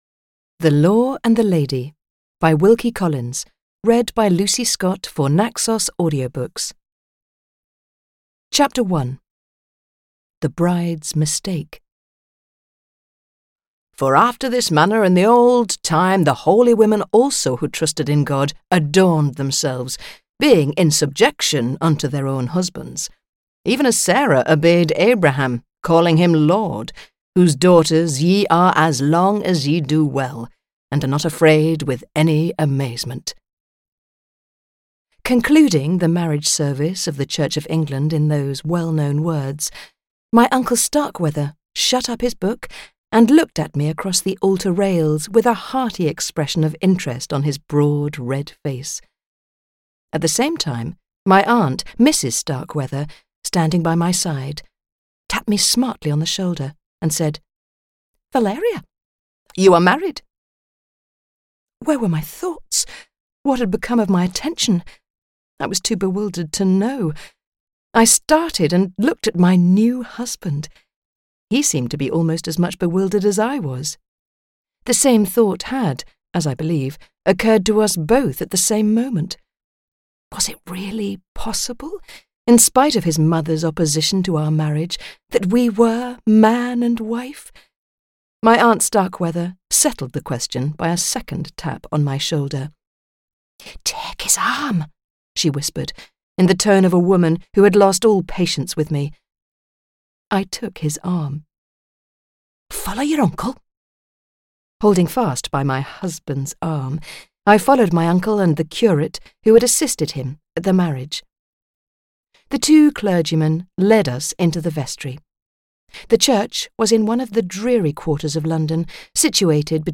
The Law and the Lady audiokniha
Ukázka z knihy